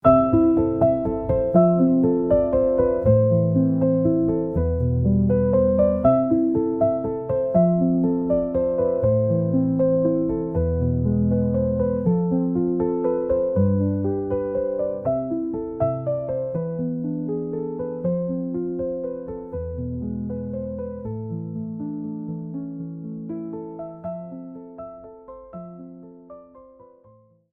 Intromusik
Maerchenjingle.ogg